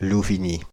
Louvigny (French pronunciation: [luviɲi]
Fr-Paris--Louvigny.ogg.mp3